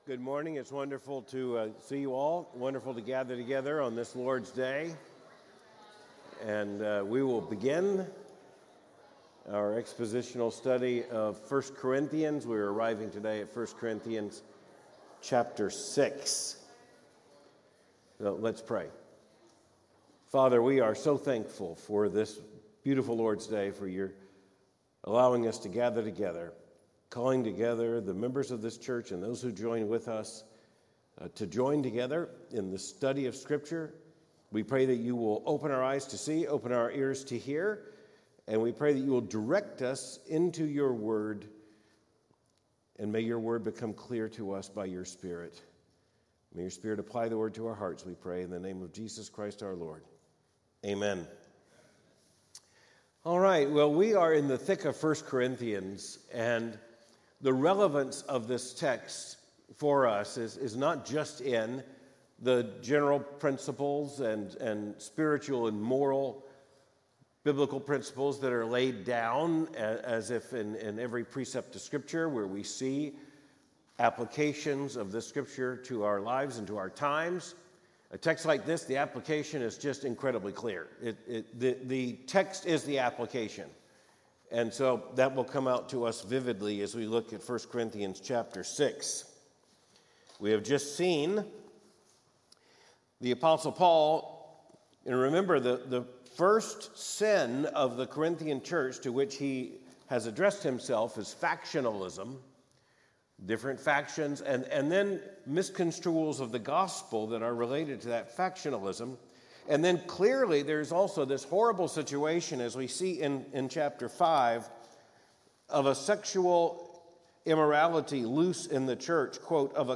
Cultural commentary from a Biblical perspective Third Avenue Baptist Church Louisville, KY 1 Corinthians 6:1–20 April 13, 2025